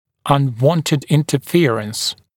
[ʌn’wɔntɪd ˌɪntə’fɪərəns]][ан’уонтид ˌинтэ’фиэрэнс]нежелательное препятствие, помеха, нежелательный контакт зубов